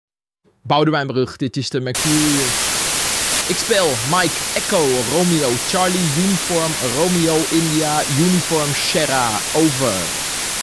Verstoorde oproep